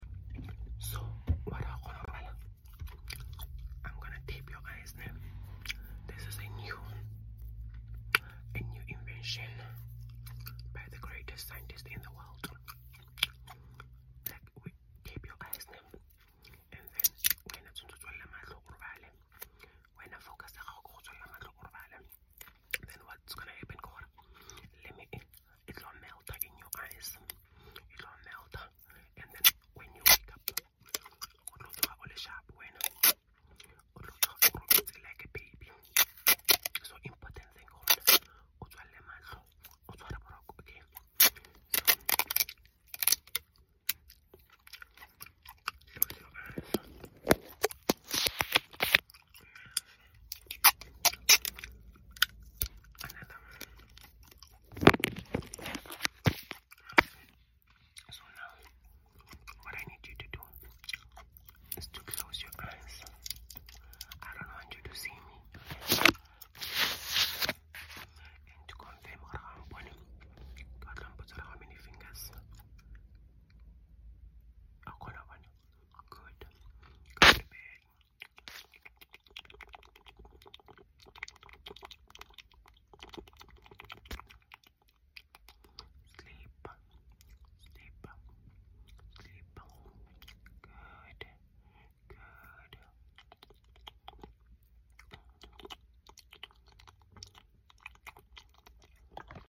Tapping your eyes shut so sound effects free download